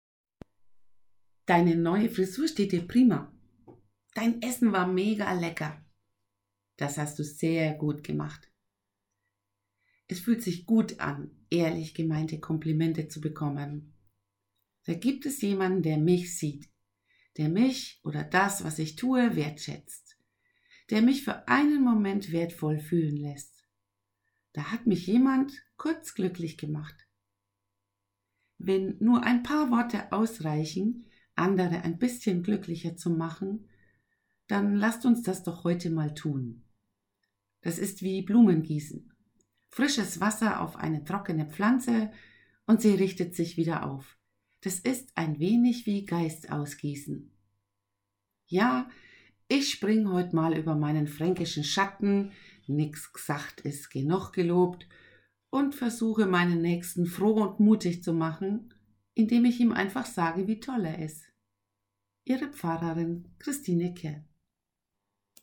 Impuls zur Tageslosung